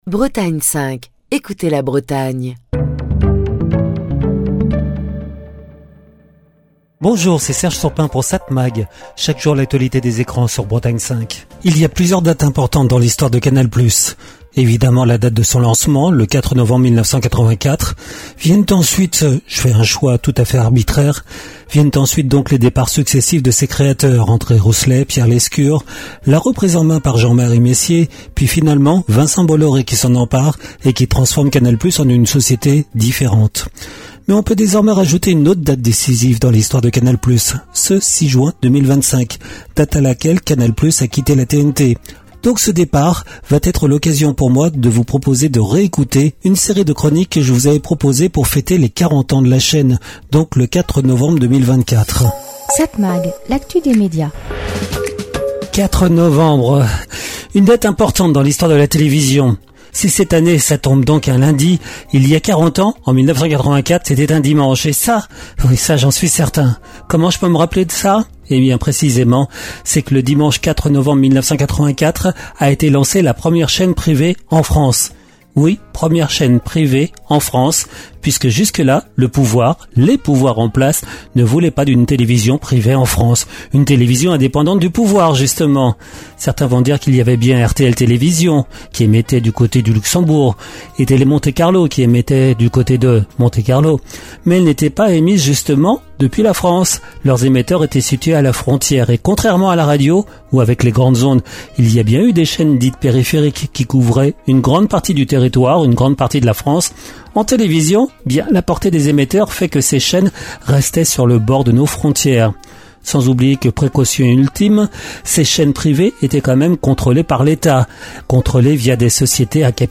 Chronique du 9 juin 2025.